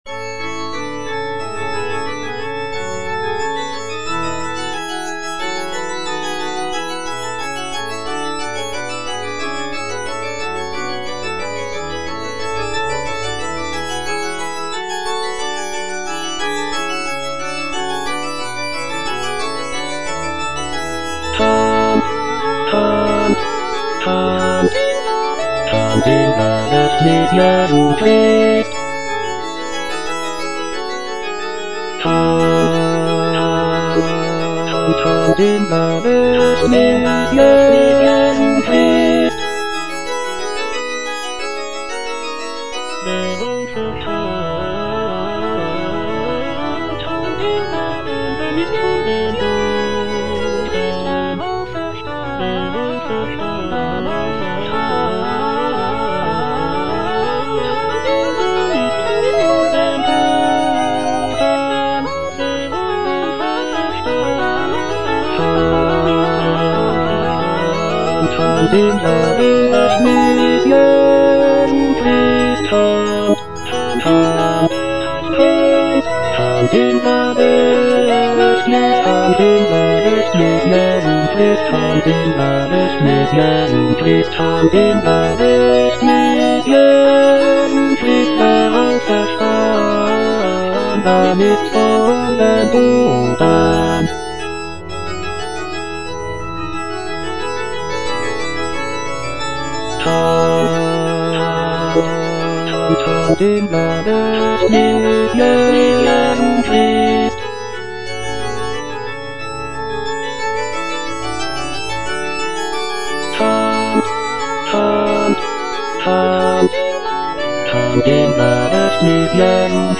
Cantata
Bass (Emphasised voice and other voices) Ads stop